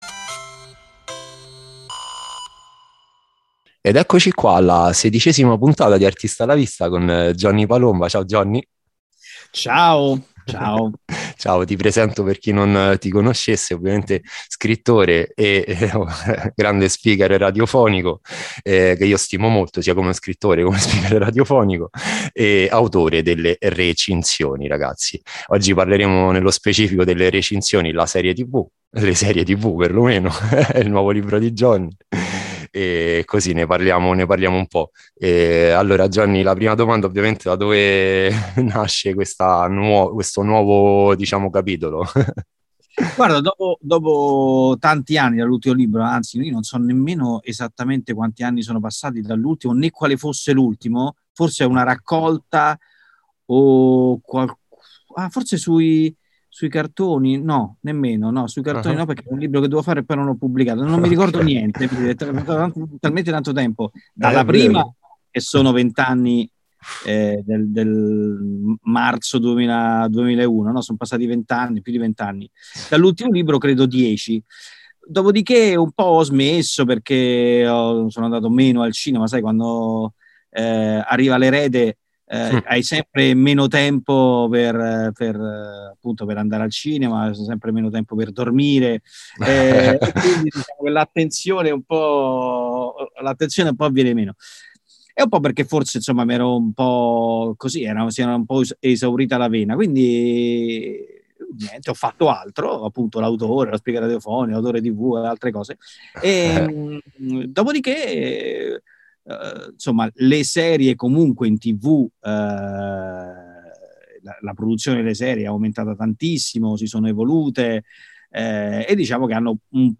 Quest’anno, cavalcando l’onda casalinga, ha pubblicato il nuovo libro comico “Recinzioni – Le Serie” e, a sorpresa, ce ne ha interpretate ben quattro. Tante risate per salutare insieme quest’anno!